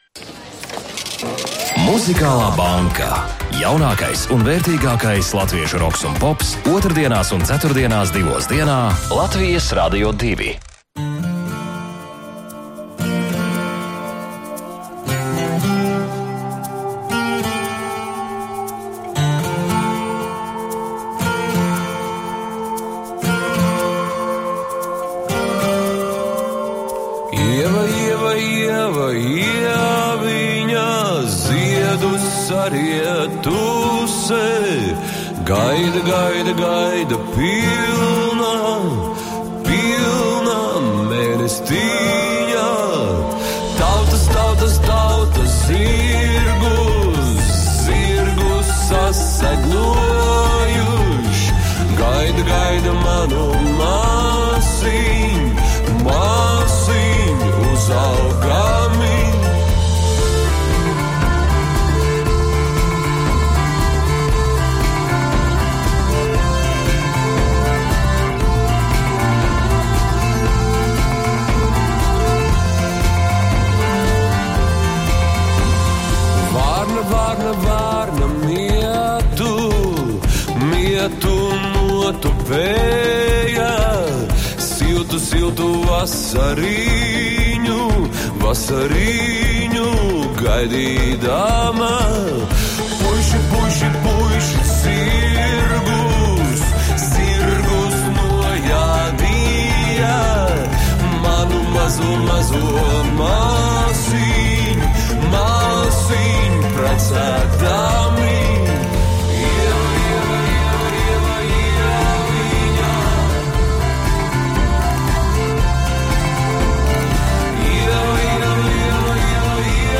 dzīvajā izpildījumā